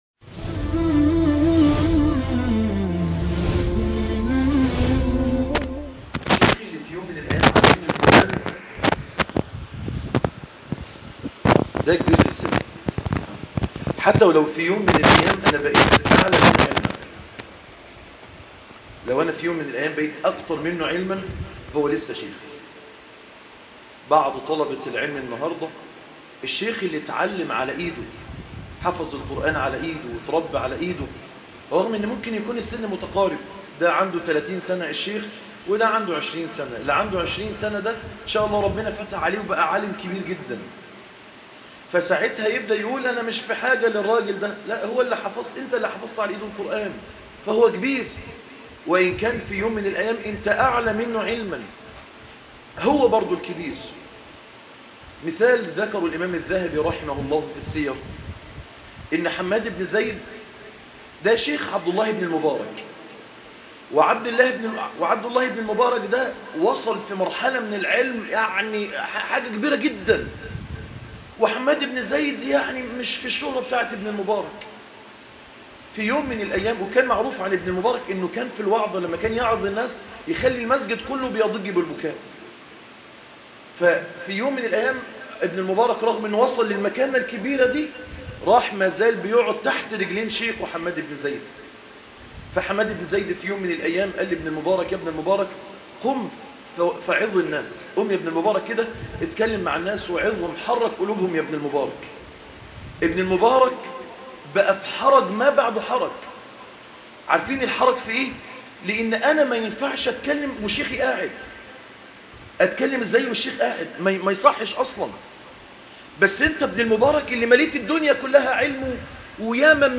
عنوان المادة ( 20/7/2018 ) احترام الكبير ( درس خطبة الجمعة )